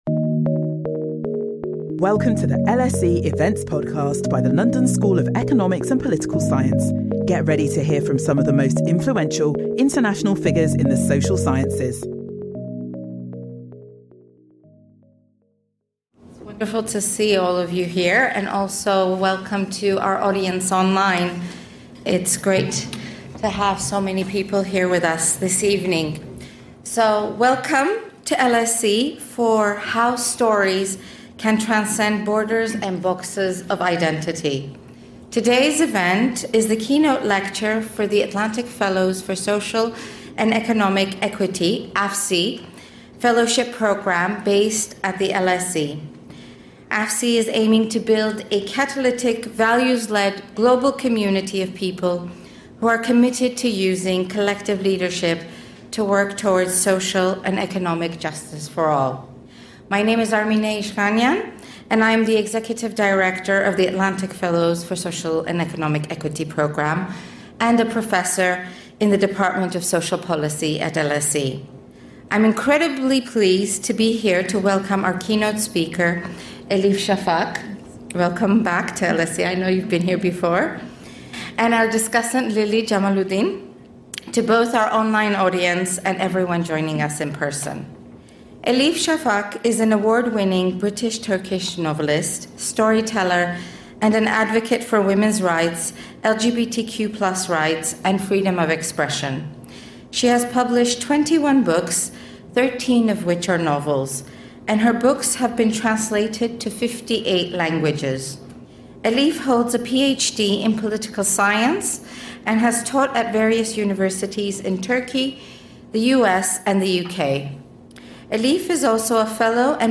By drawing upon multiple disciplines and weaving these threads into the broader practice of literary arts, the Turkish-British writer Elif Shafak offers an inspirational talk about our world today, the stories that bring us together, and the silences that keep us apart.